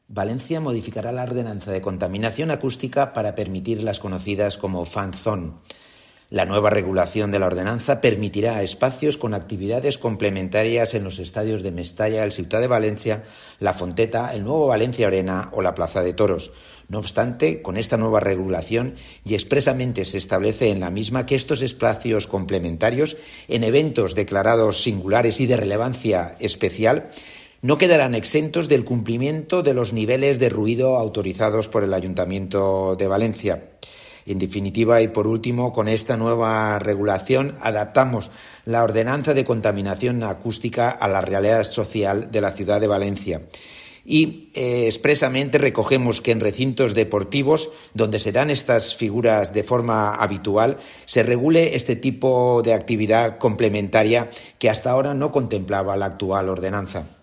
Corte de voz de Carlos Mundina, concejal de Mejora Climática, Acústica y Eficiencia Energética.